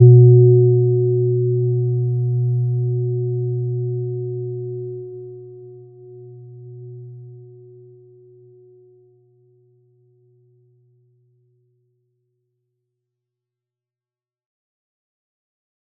Gentle-Metallic-1-B2-mf.wav